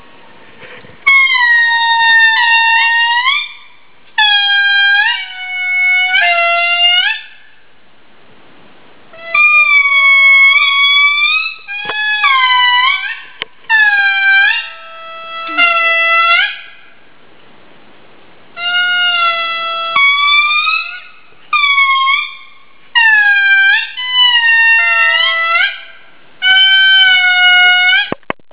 Parc Andasibe, retour à Tana
cri caractéristique.
indri.wav